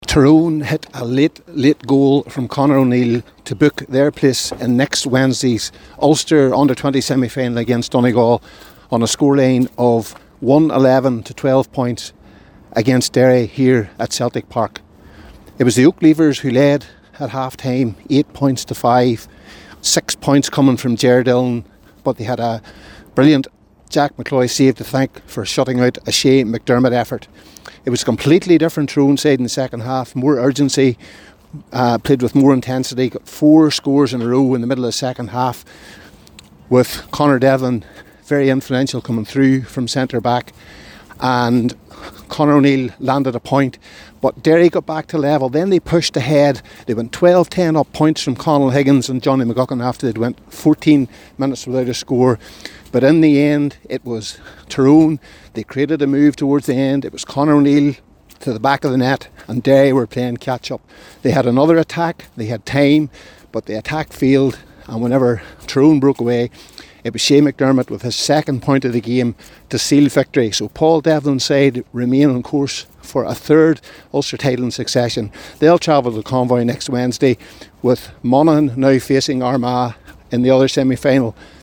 U20-report.mp3